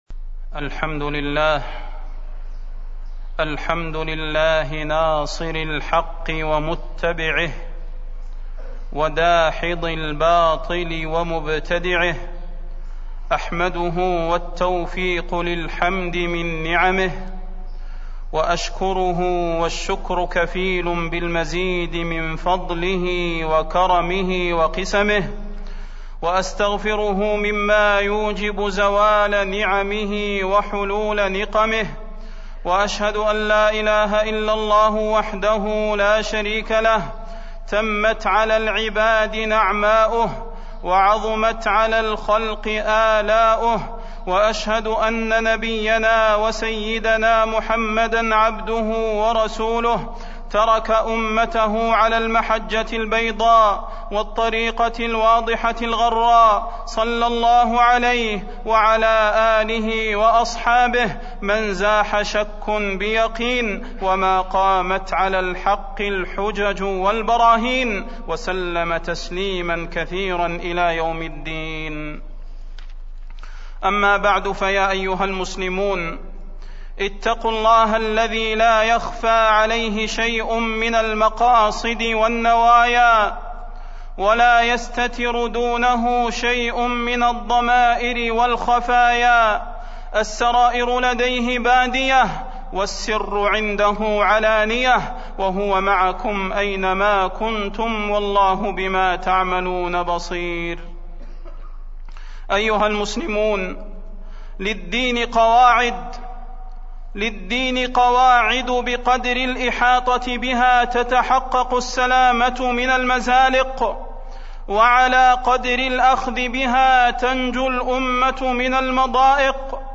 تاريخ النشر ٣ جمادى الأولى ١٤٢٦ هـ المكان: المسجد النبوي الشيخ: فضيلة الشيخ د. صلاح بن محمد البدير فضيلة الشيخ د. صلاح بن محمد البدير الفتوى